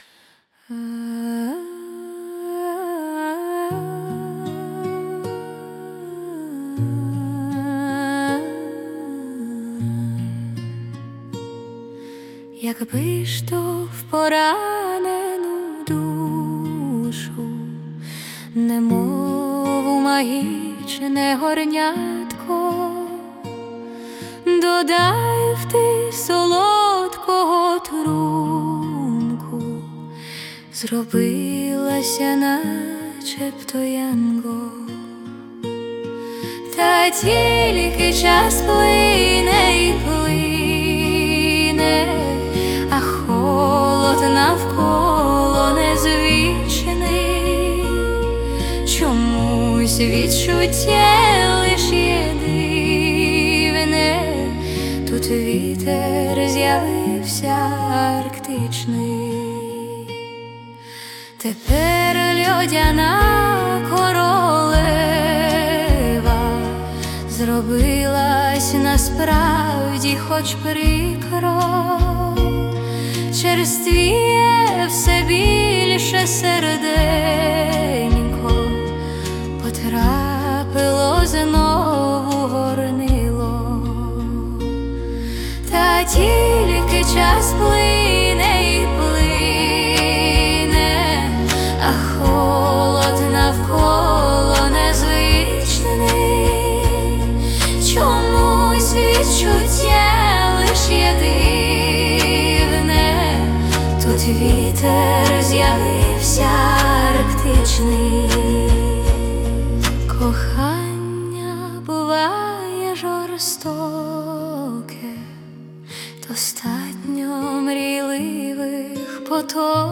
СТИЛЬОВІ ЖАНРИ: Ліричний
Чудова пісня. 16 give_rose
Чарівна пісня, дуже ніжна! give_rose give_rose give_rose